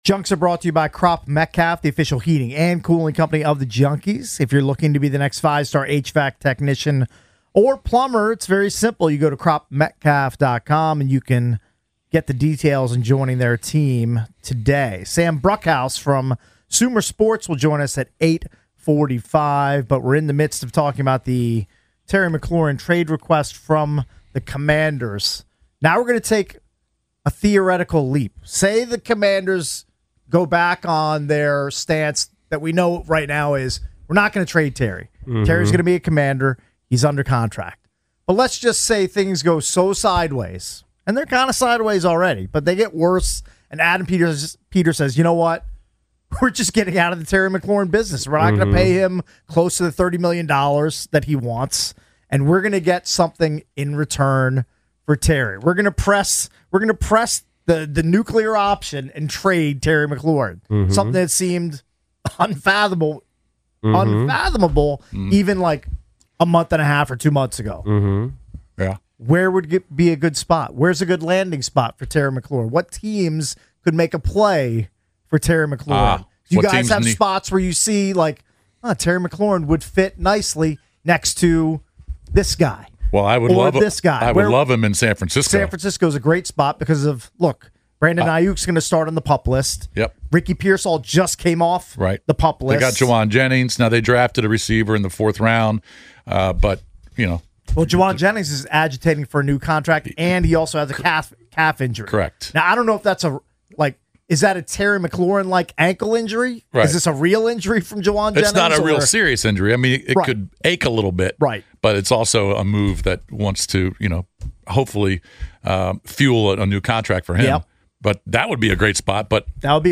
The Sports Junkies discuss Terry McLaurin's contract negotiations with callers.